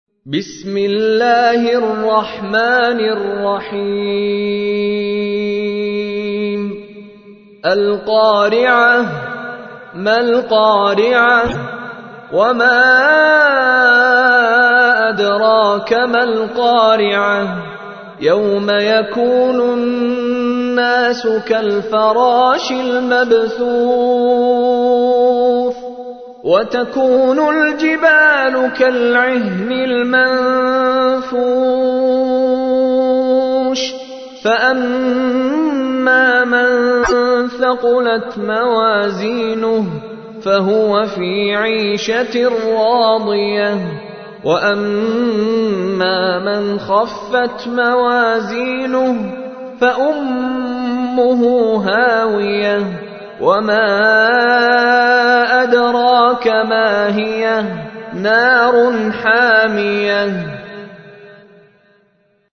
تحميل : 101. سورة القارعة / القارئ مشاري راشد العفاسي / القرآن الكريم / موقع يا حسين